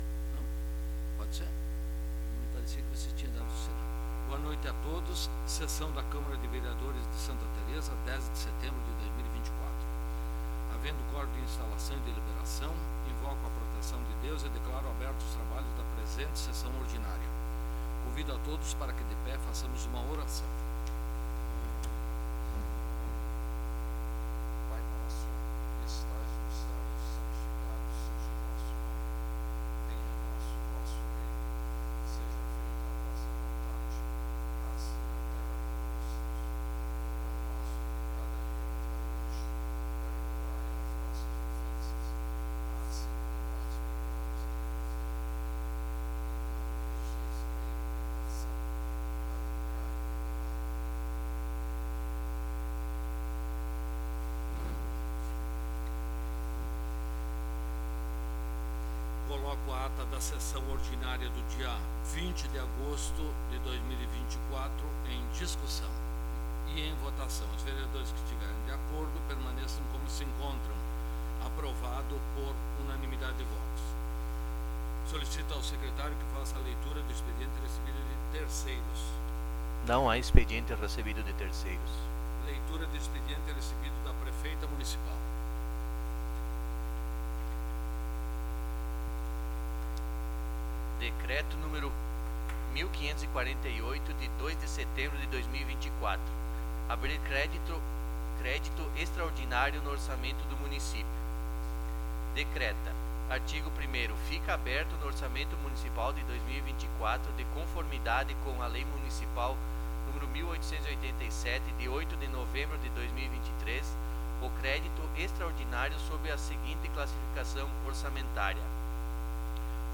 15ª Sessão Ordinária de 2024
15ª Sessão Ordinária de 2024 Data: 10 de setembro de 2024 Horário: 19:00 Local: Câmara Municipal de Vereadores de Santa Tereza .